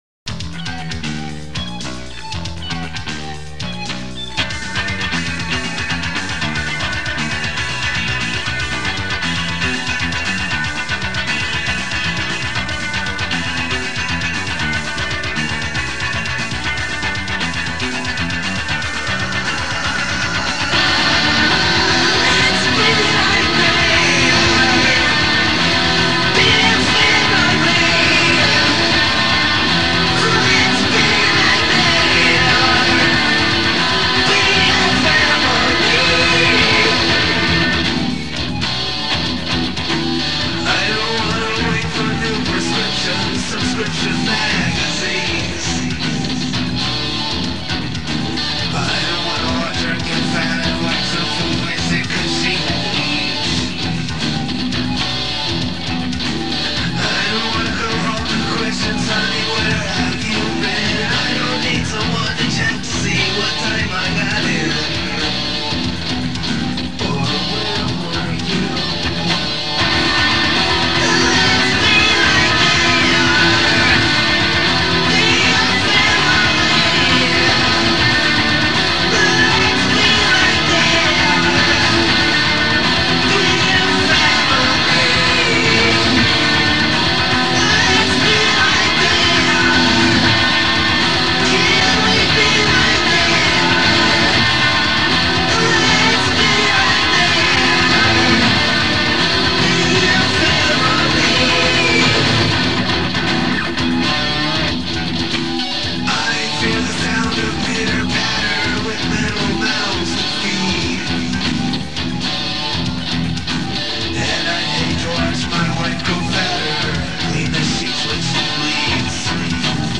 They didn't have a drummer, so they used a drum machine.
Very rough, early versions
with a home recorder and drum machine